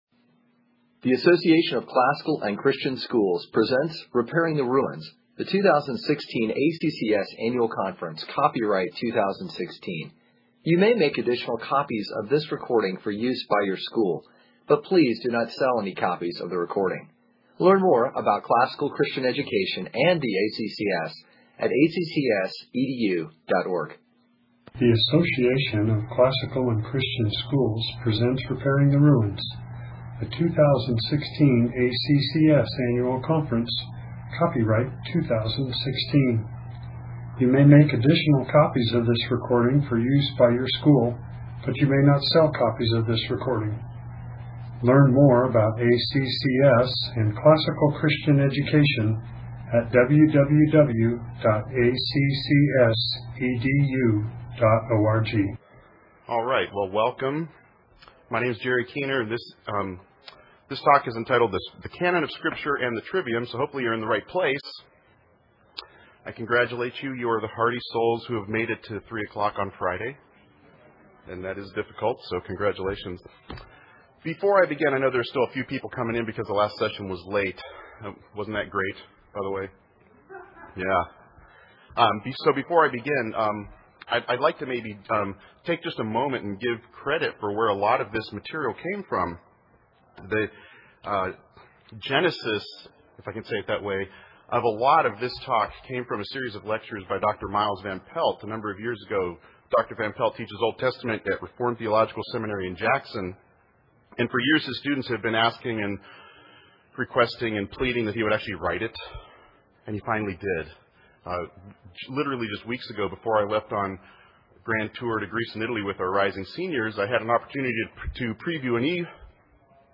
2016 Foundations Talk | 1:01:14 | All Grade Levels, Bible & Theology